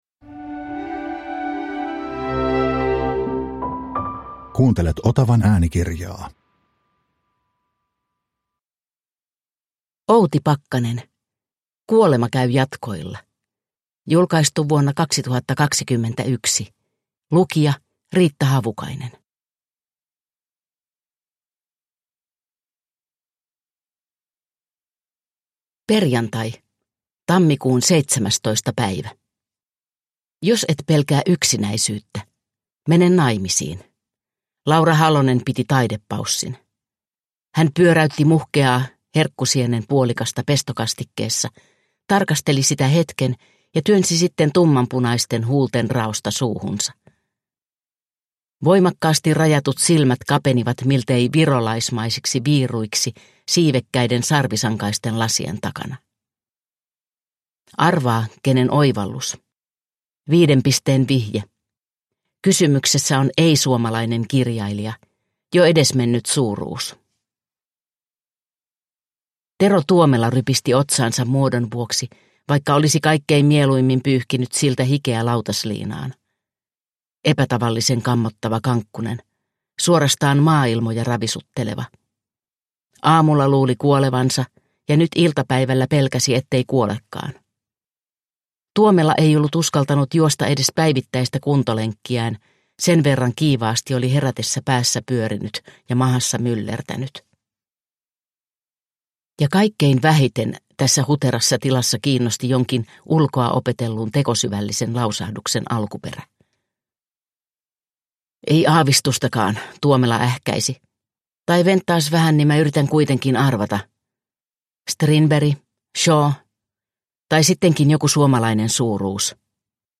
Kuolema käy jatkoilla – Ljudbok – Laddas ner